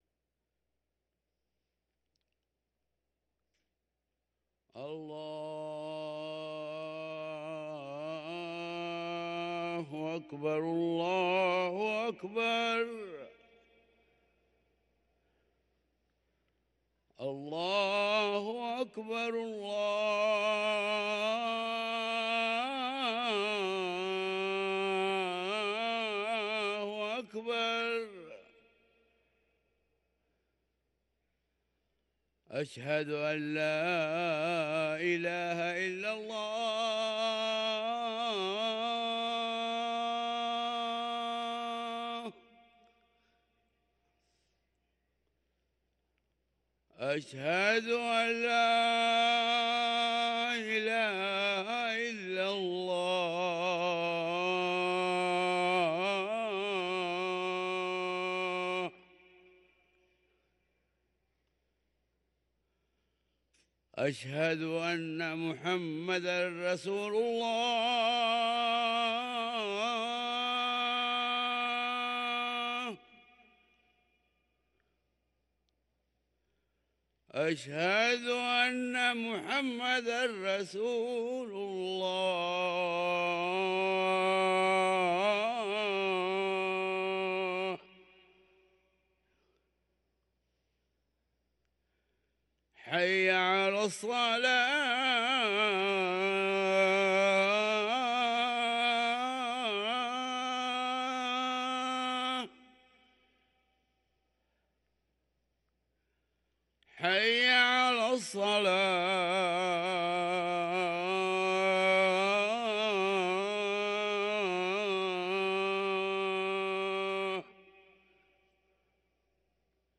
أذان الفجر
ركن الأذان